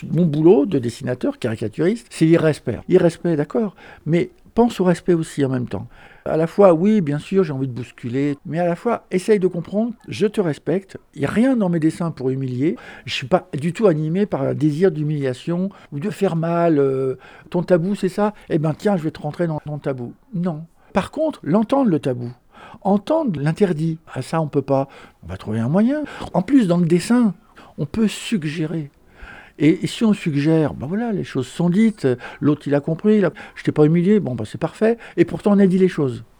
ITC Plantu 2-Conférence expo Bonneville pour la Paix